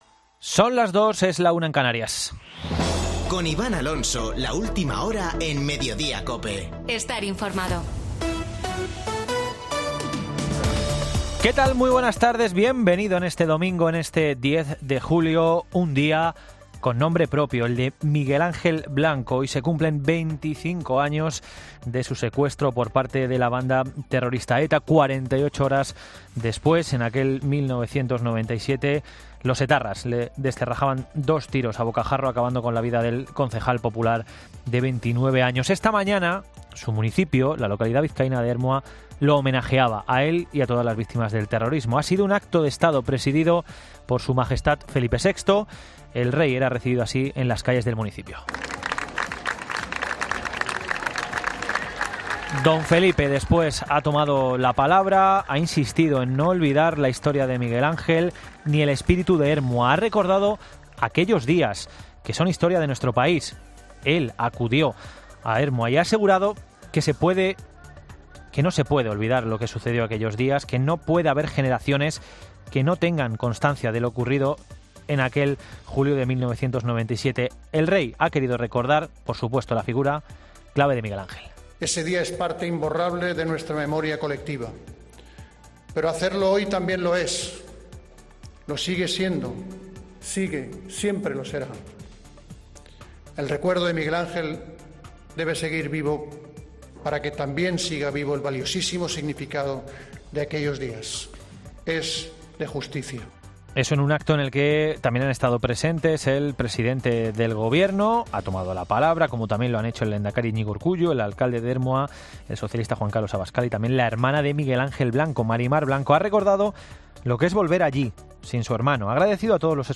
Boletín de noticias de COPE del 10 de julio de 2022 a las 14:00 horas